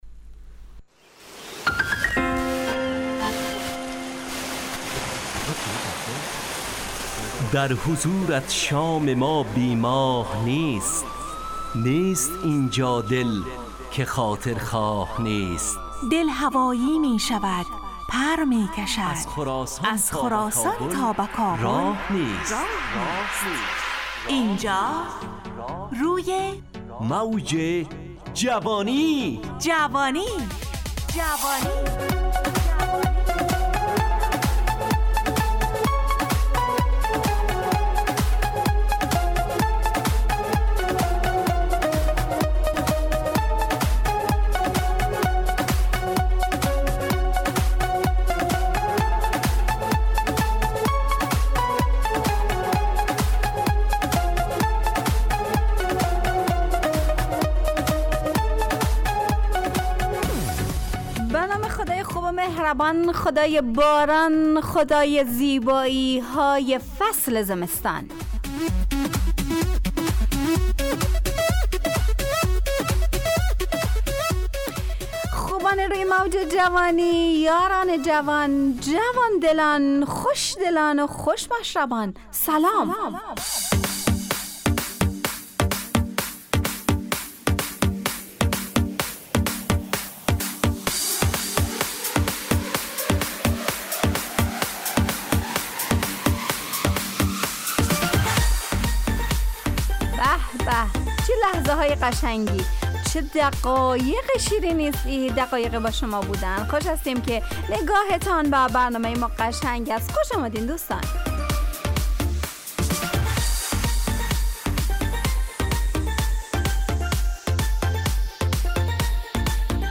روی موج جوانی، برنامه شادو عصرانه رادیودری. از شنبه تا پنجشنبه ازساعت 17 الی 17:55 طرح موضوعات روز، وآگاهی دهی برای جوانان، و.....بخشهای روزانه جوان پسند....
همراه با ترانه و موسیقی .